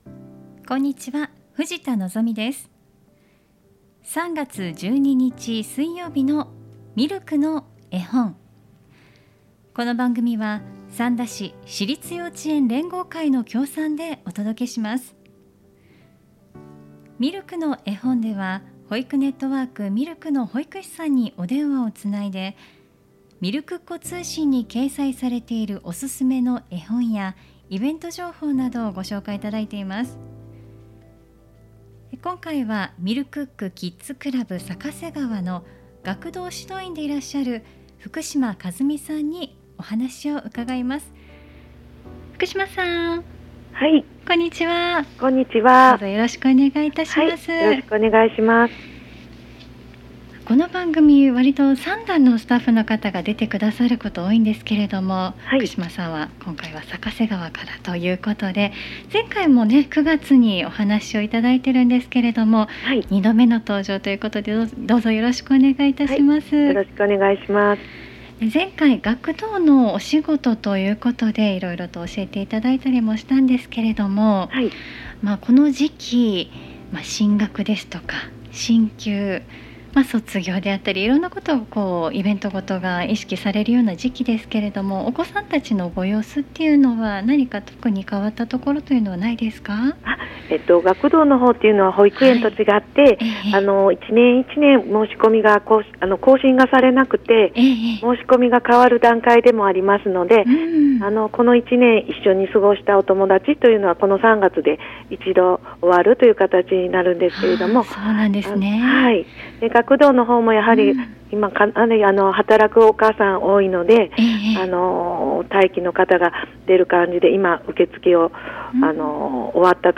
保育ネットワーク・ミルクの保育士さんにお電話をつないで、みるくっ子通信に掲載されているおすすめの絵本やイベント・施設情報などお聞きします。